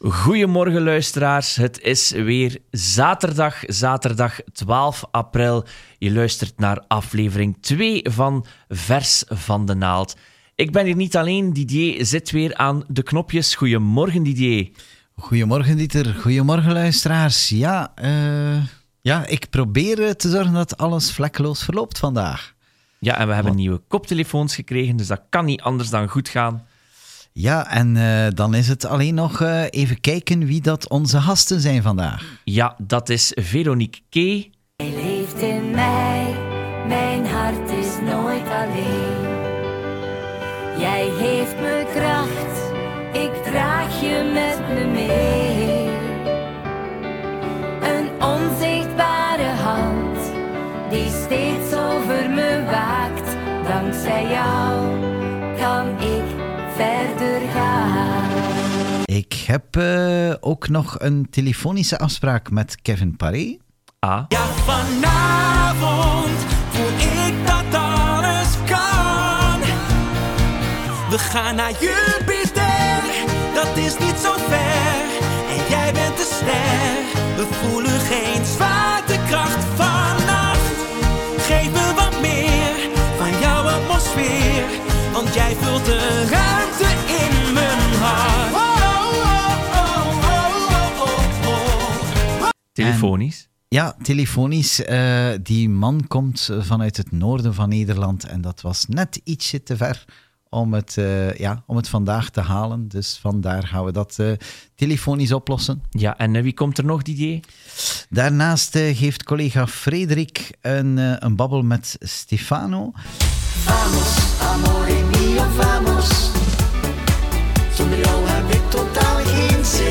Deze podcast zonder de muziek erbij.